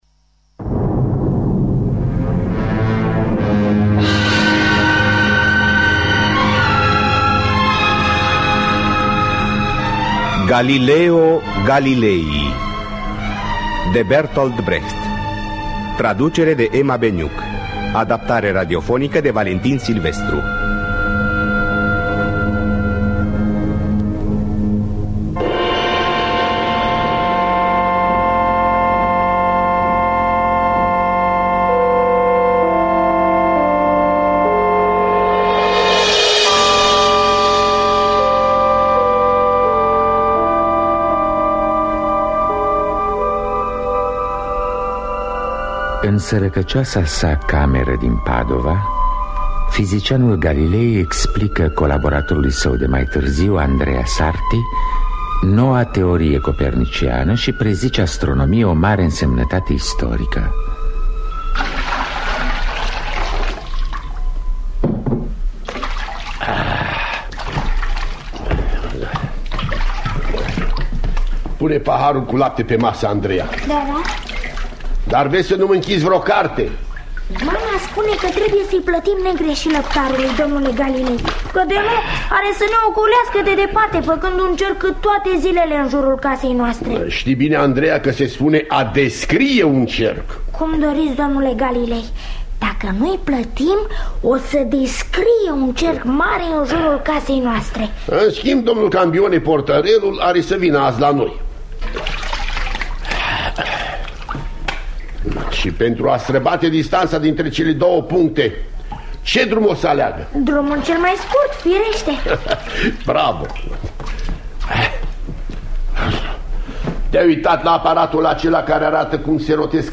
„Galileo Galilei” de Bertolt Brecht – Teatru Radiofonic Online